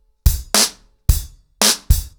BlackMail-110BPM.5.wav